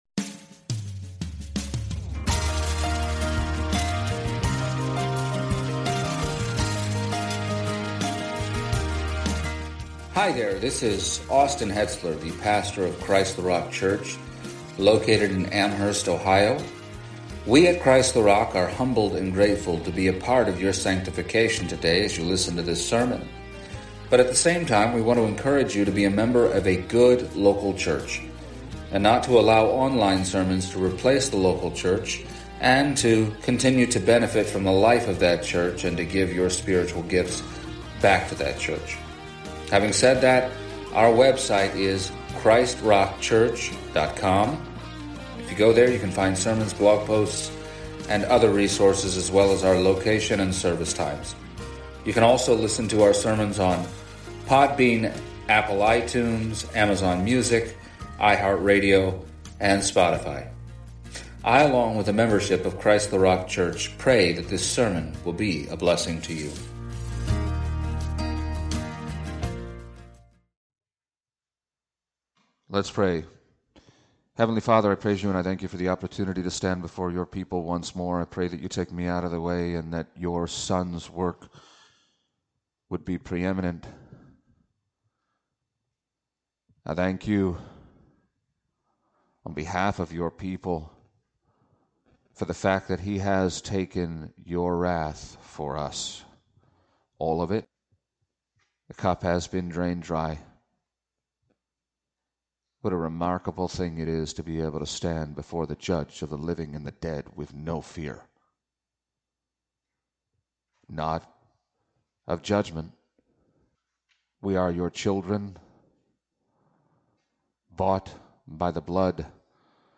Exposition of the Epistle to the Hebrews Passage: Hebrews 3:12-4:13 Service Type: Sunday Morning “Not one day of rest